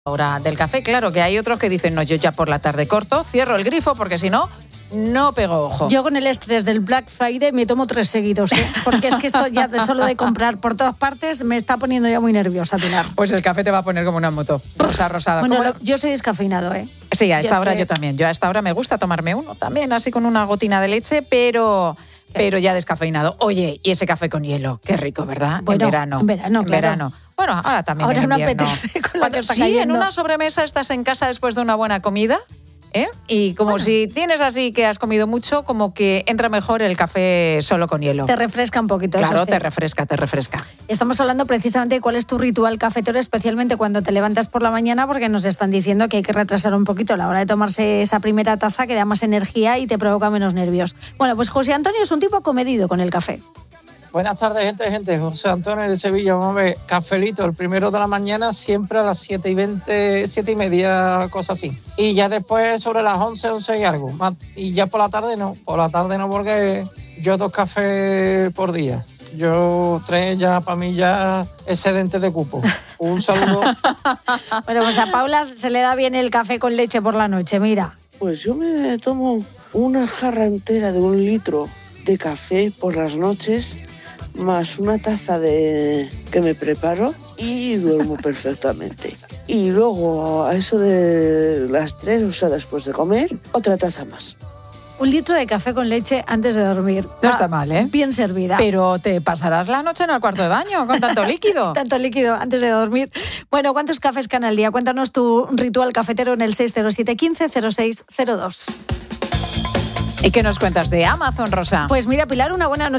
Los oyentes de 'La Tarde' desvelan sus sorprendentes rituales con el café, desde la abstinencia vespertina hasta el consumo de más de un litro nocturno